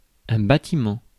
Ääntäminen
France: IPA: [bɑ.ti.mɑ̃]